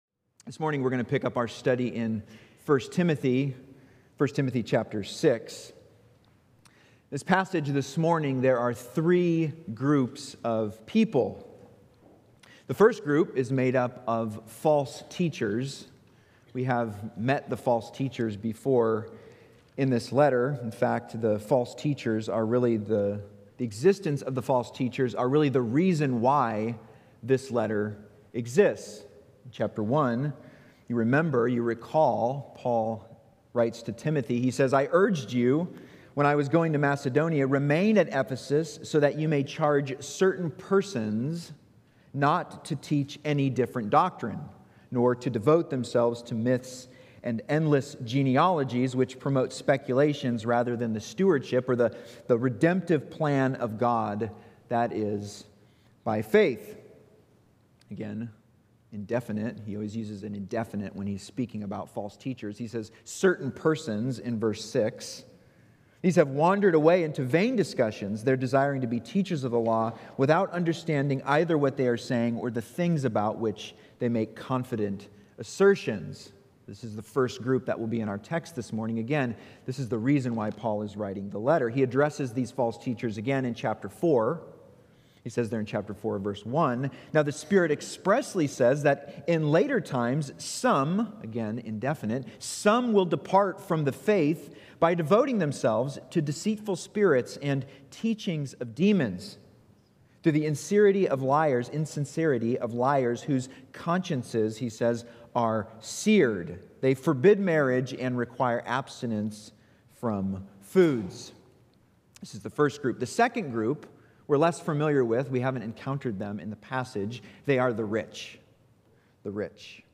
Sermons by Rosedale Bible Church